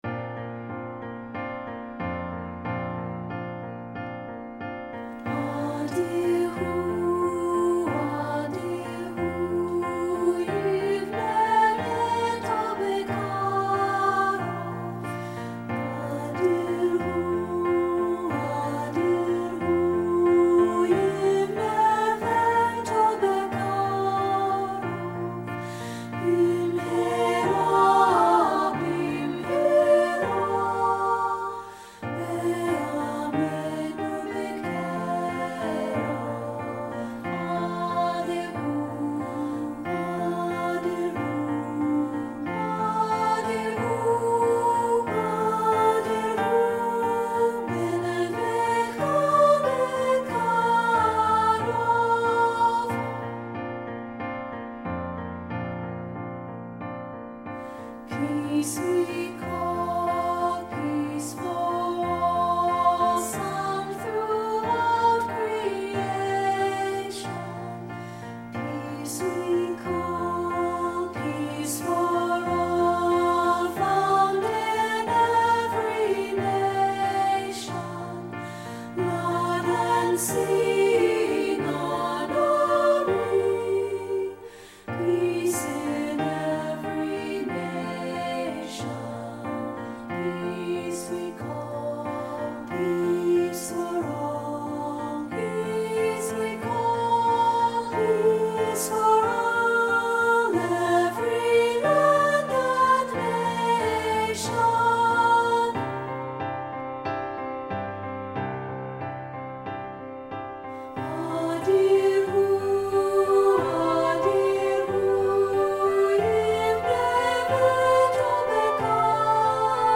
Voicing: SA